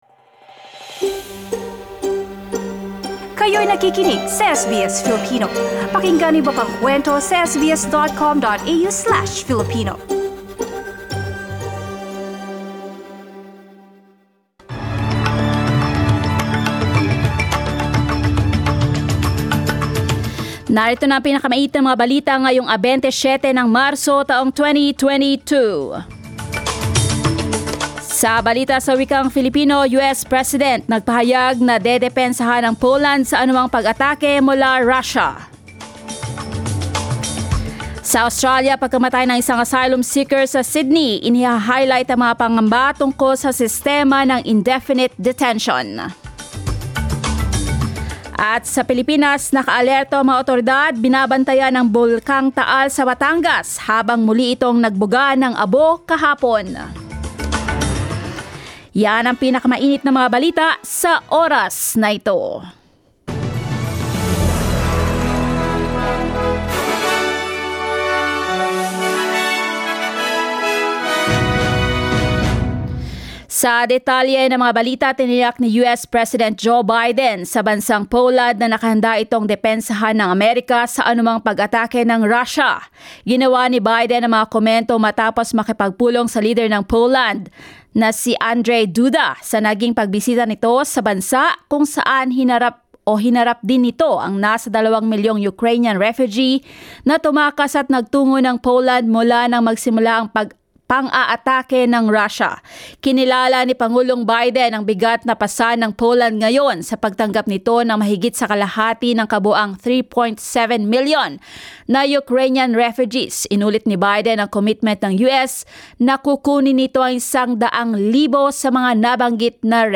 SBS News in Filipino, Sunday 27 March